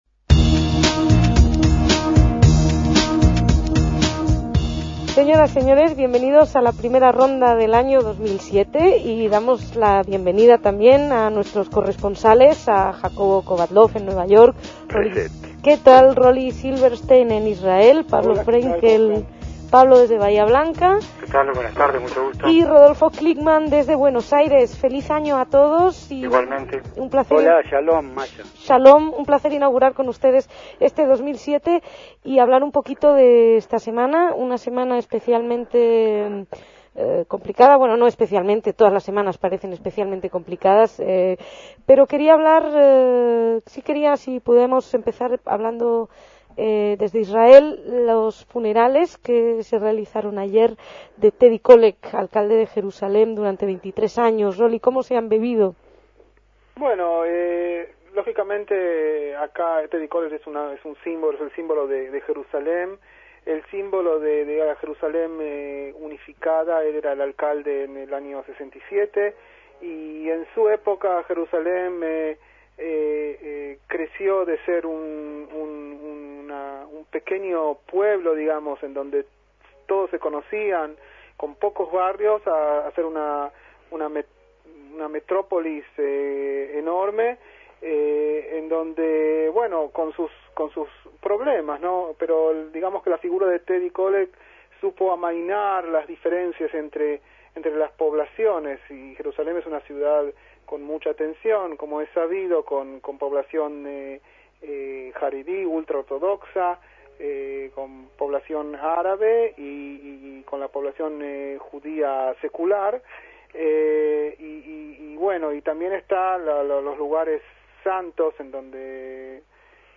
La ronda de corresponsales